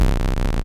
未来的复古777大鼓 " ride3
描述：Future Retro 777是一个模拟bassline机器，有一个漂亮的集成音序器。它有灵活的路由可能性和两个振荡器，所以也可以进行实验，创造一些鼓声。这里有一些。
标签： 模拟 FR-777 futureretro
声道立体声